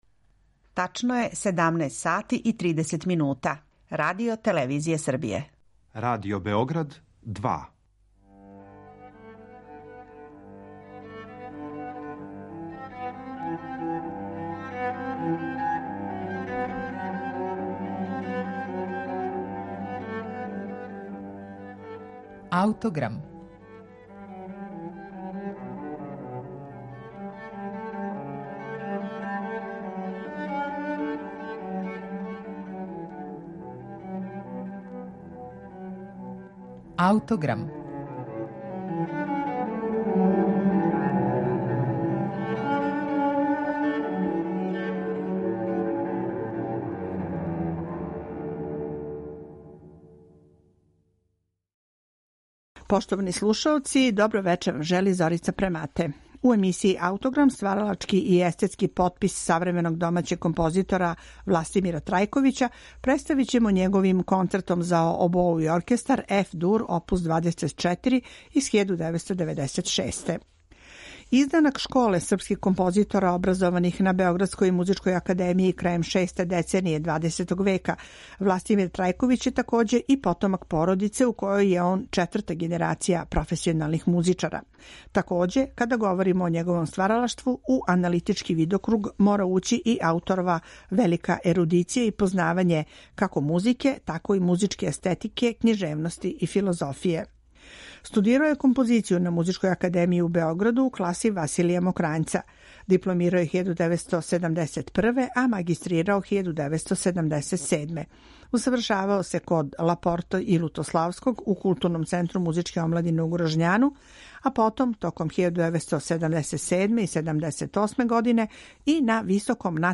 концертантна дела
шармантни и ведри поздрав француском неокласицизму